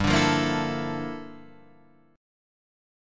Gm13 Chord